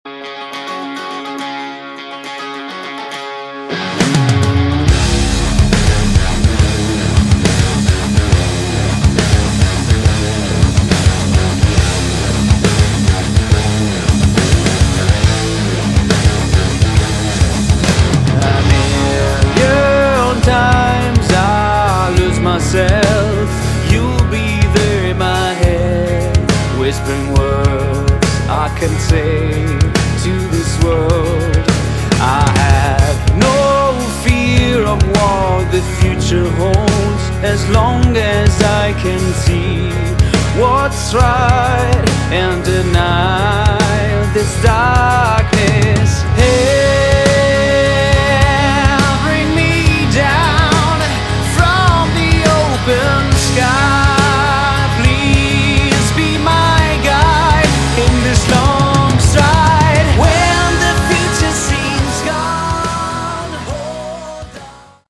Category: Modern Hard Rock
vocals, guitars
bass
drums